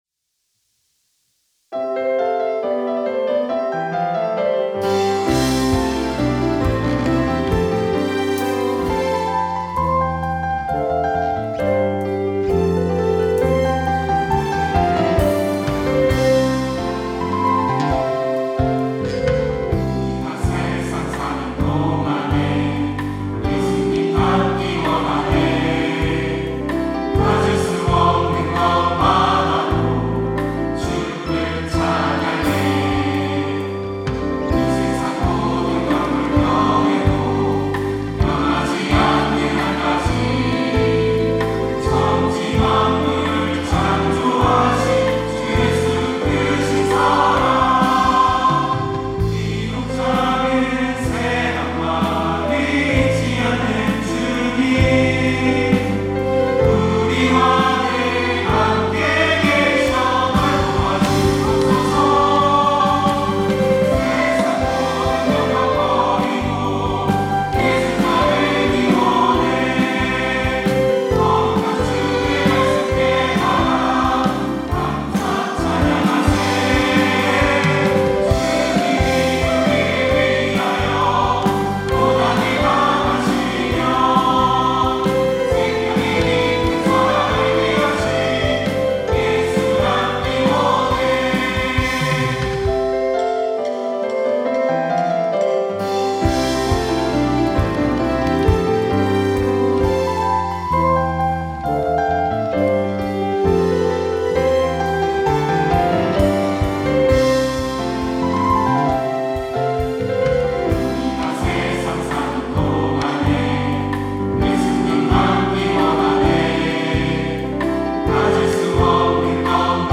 특송과 특주 - 예수 닮기 원해
청년부 2팀